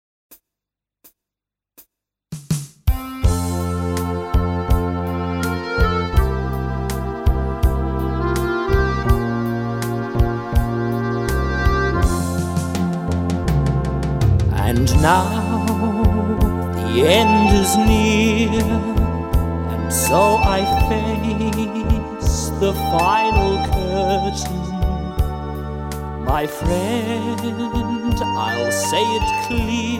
MIDI accordion with vocals